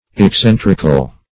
Search Result for " excentrical" : The Collaborative International Dictionary of English v.0.48: Excentric \Ex*cen"tric\, Excentrical \Ex*cen"tric*al\, a. 1.